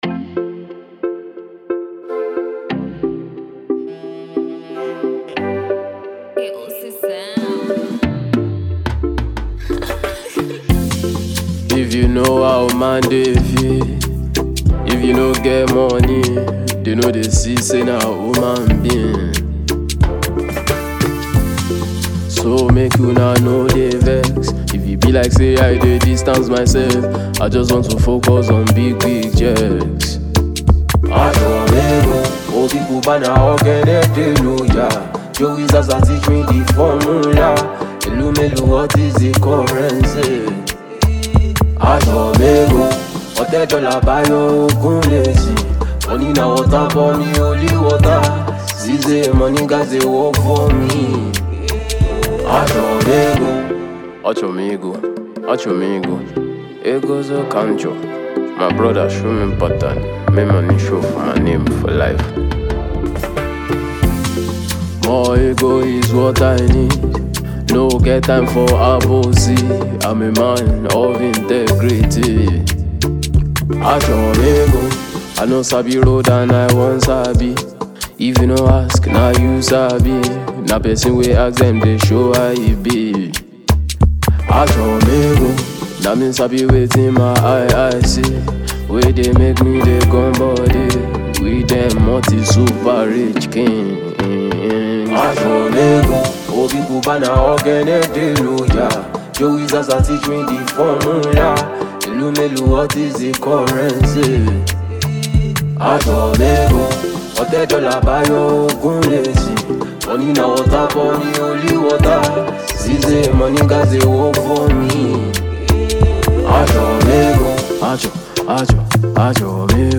Afro-fusion, Afrobeat, Hip-Hop